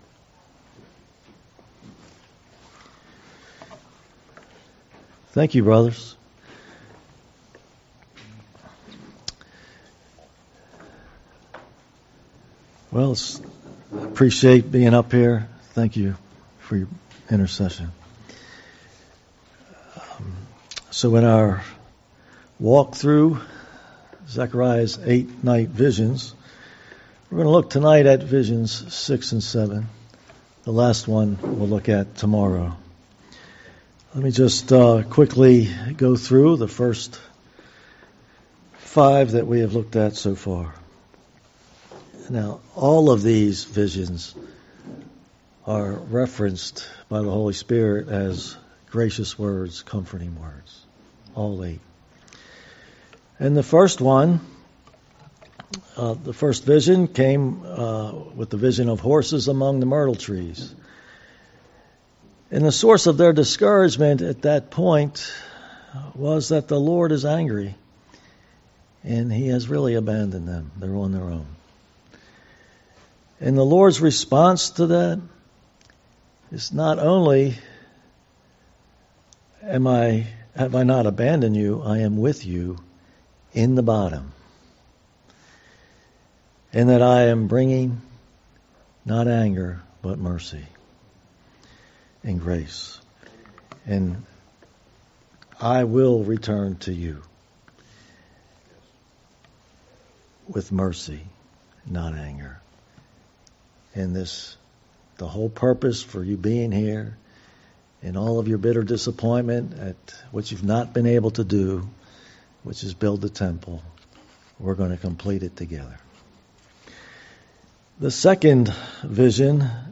Location: Denton, MD
Zechariah's 8 Night Visions Conference: 2025 Spring Men's Weekend - Zechariah's Night Visions Audio File